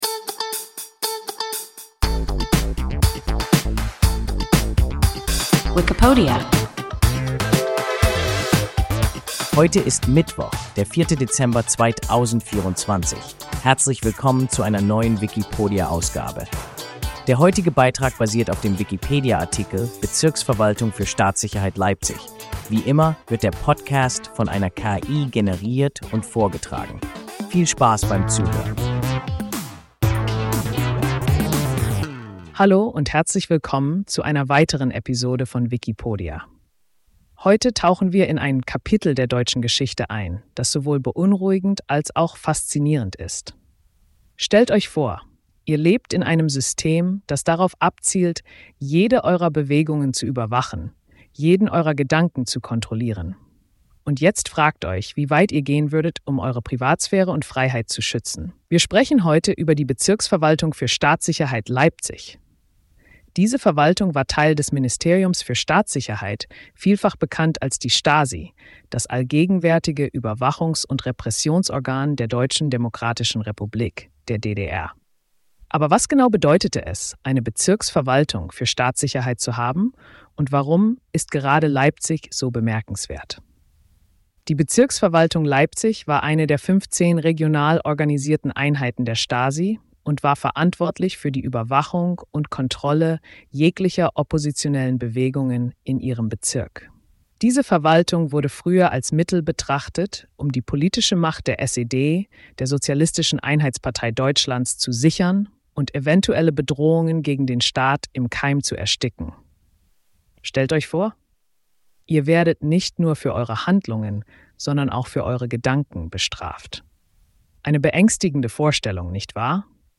Bezirksverwaltung für Staatssicherheit Leipzig – WIKIPODIA – ein KI Podcast